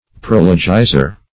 Prologizer \Pro"lo*gi`zer\, n. One who prologizes.